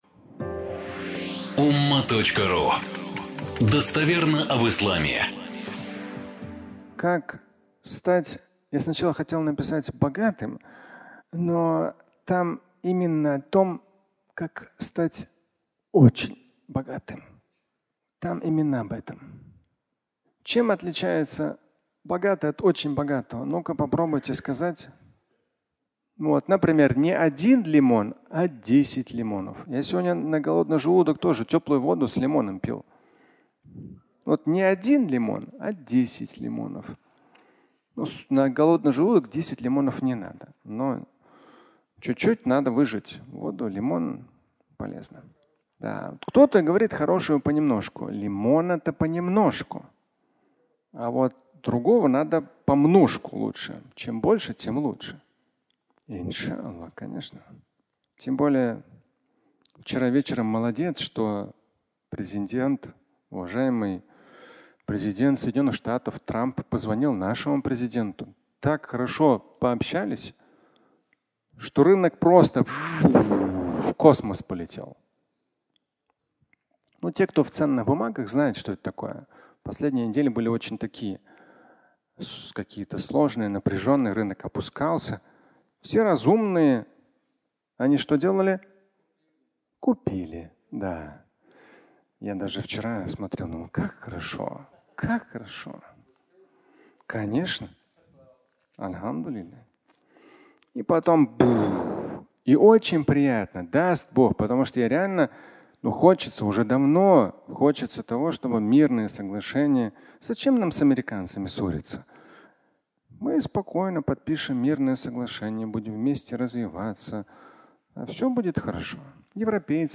(аудиолекция)